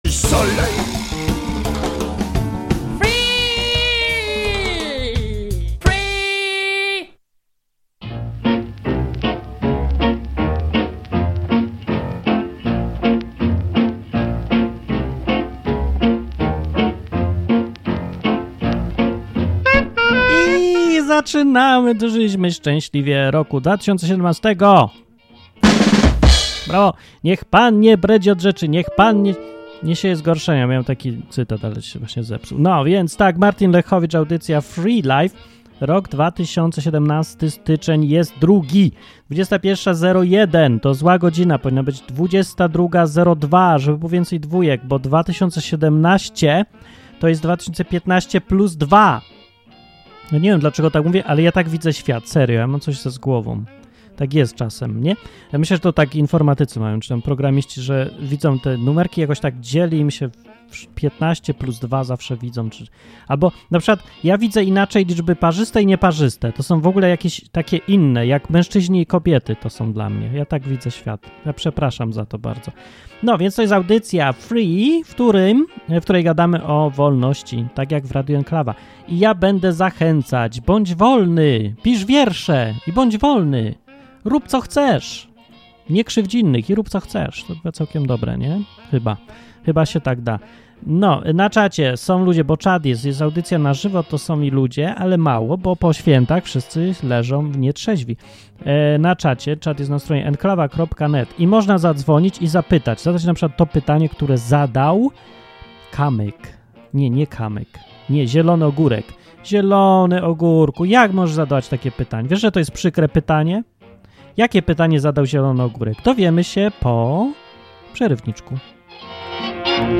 Program dla wszystkich, którzy lubią luźne, dzikie, improwizowane audycje na żywo.